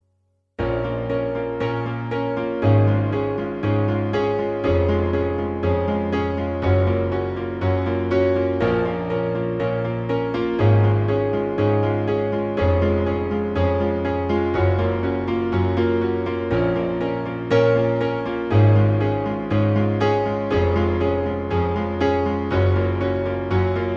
With Full Intro Pop (2010s) 4:25 Buy £1.50